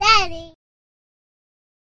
알림음 8_GirlSaysDaddySound.mp3